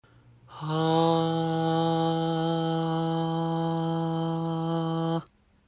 声に息を混ぜるイメージで空気の量を増やしましょう。
ハアーーー
ウィスパーボイスといわれる「ささやく」ような発声です。
ウィスパーボイス.mp3